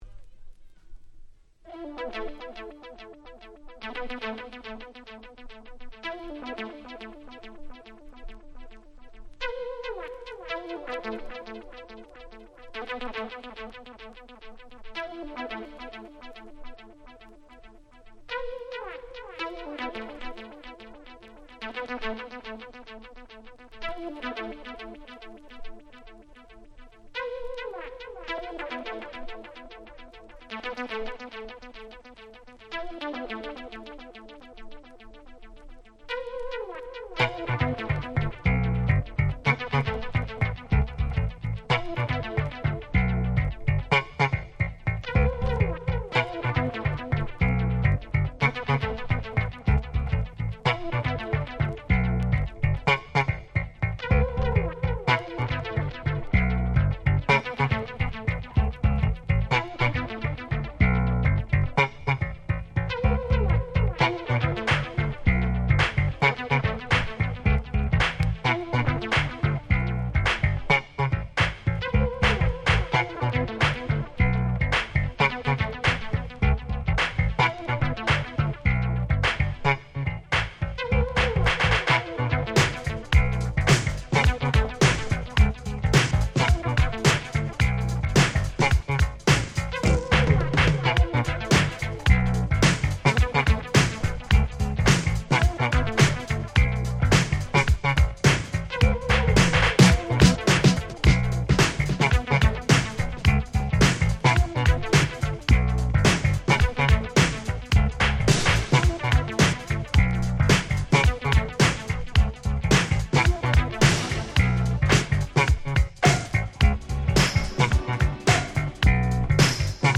87' Nice Dance Classics / Disco コンピレーション！！